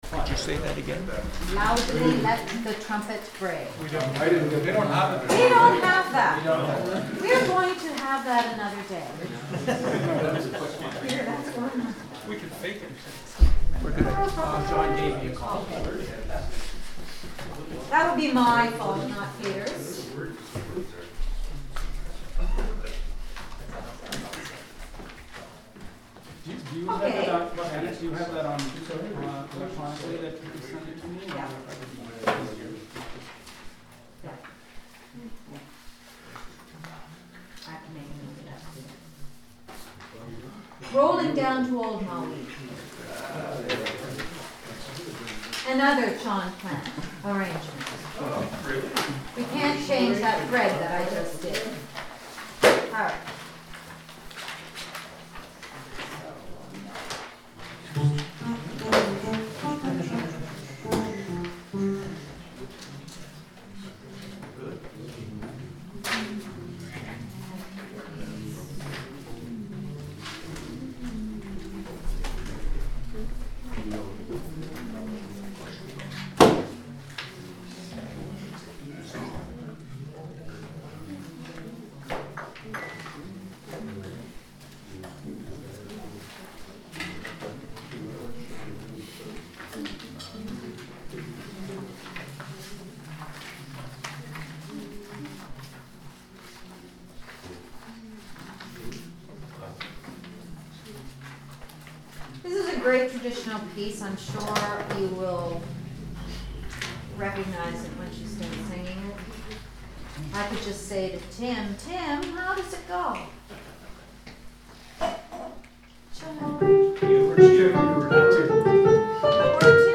Coastal Voices Men's Choir
Rehearsal Files